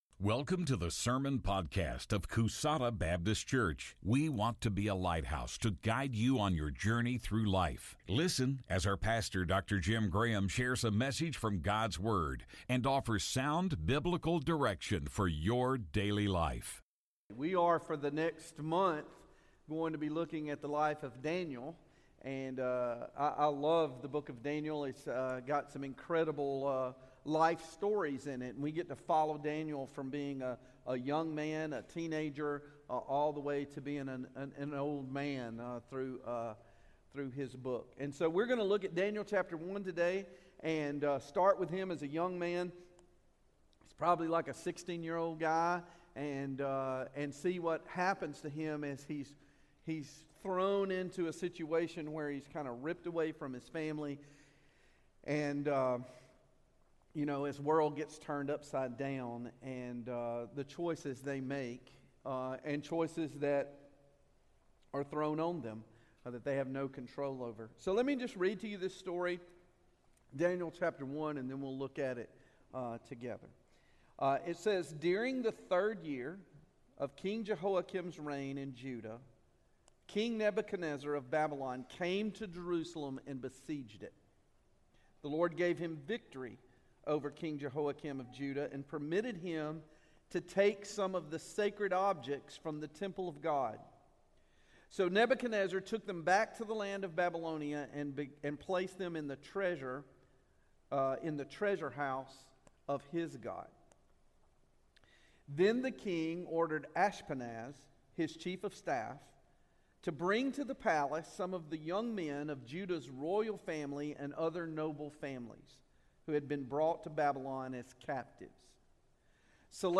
Sermons and other content from Coosada Baptist Church in Coosada, Alabama